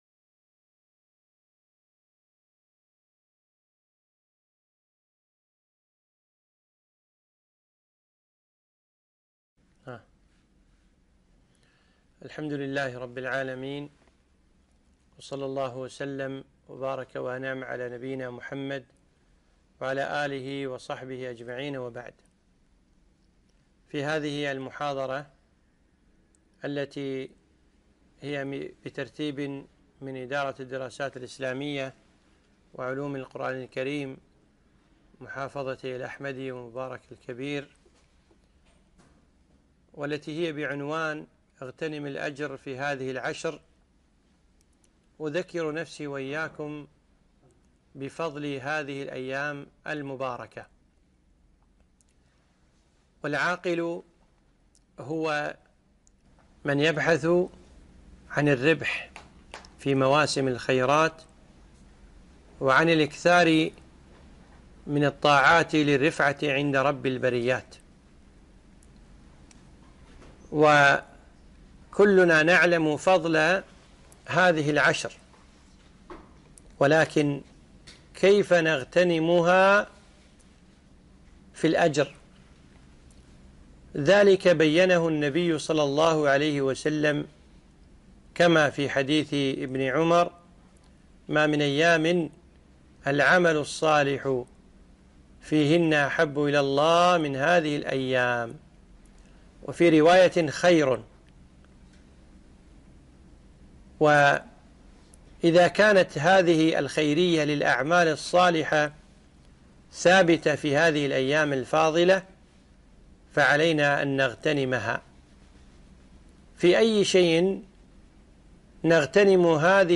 محاضرة اغتنم الأجر في هذه العشر